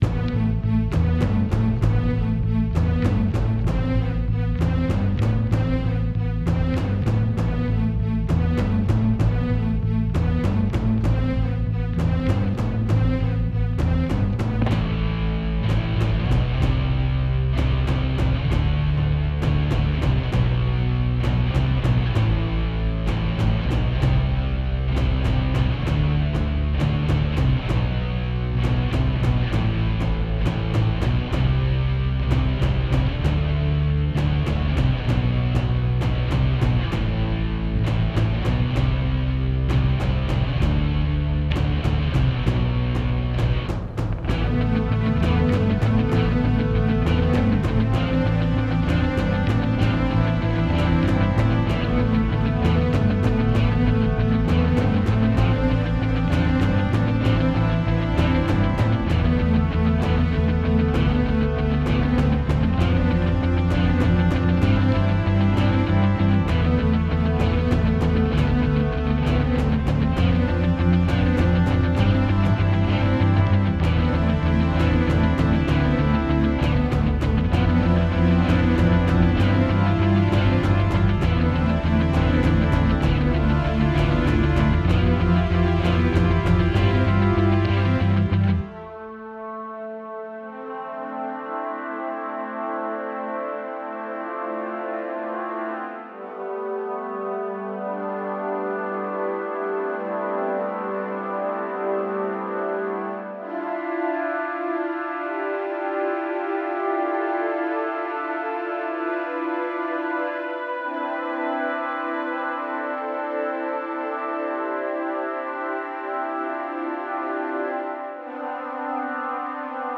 True Adversity(game adventure track) *UPDATED 1